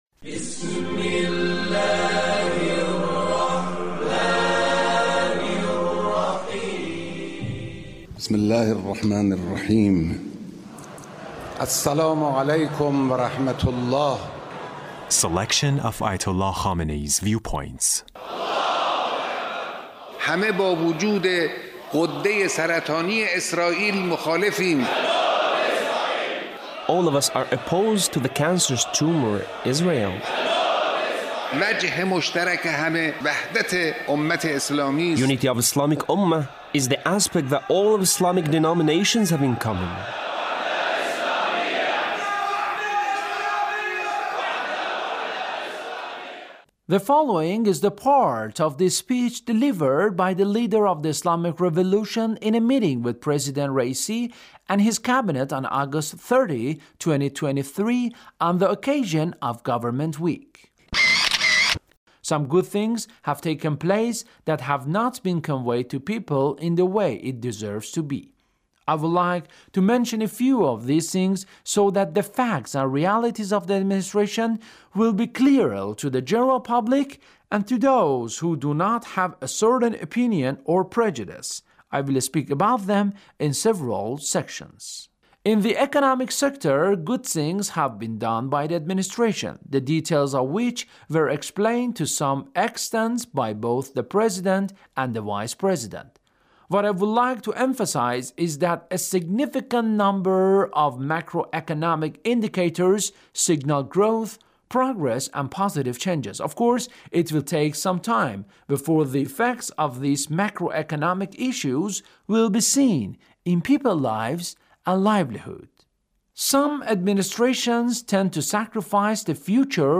Leader's Speech with Government Officials